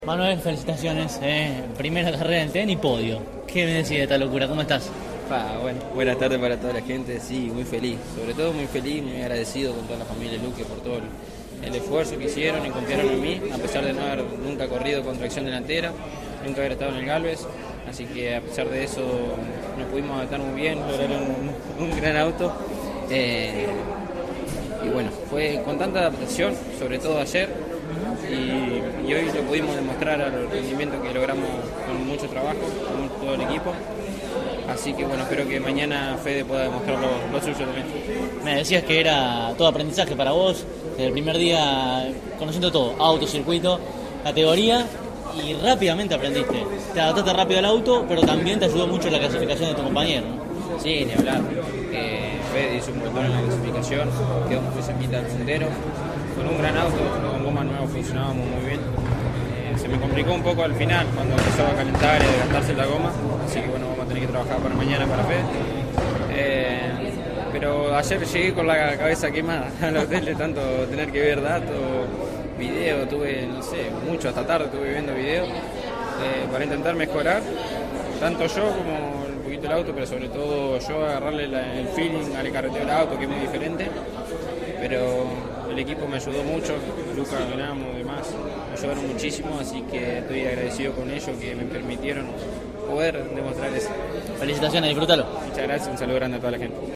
CÓRDOBA COMPETICIÓN estuvo presente en la fecha realizada en el «Autódromo Oscar y Juan Gálvez» y dialogó con los protagonistas de esa primera competencia de la cita porteña.